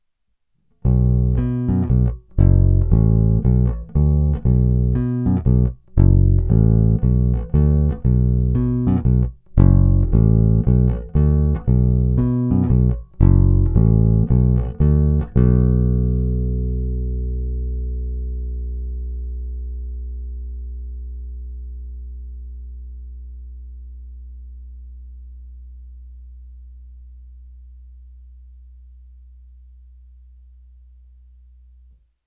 Je hutný, vrčivý, zvonivý, s bohatými středy, s příjemnými výškami a masívními basy.
Není-li uvedeno jinak, následující ukázky jsou pořízeny rovnou do vstupu zvukové karty a kromě normalizace ponechány bez jakéhokoli postprocesingu. Tónová clona byla vždy plně otevřená.
Hra mezi snímačem a krkem